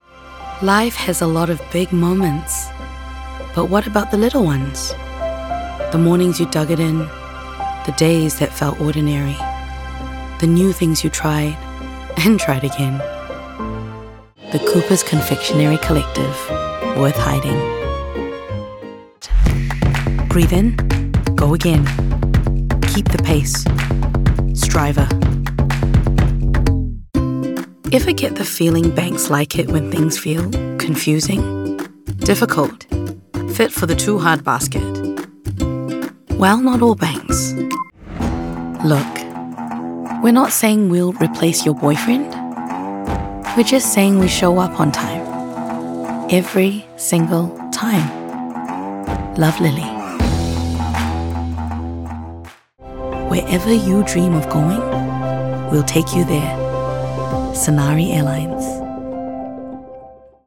husky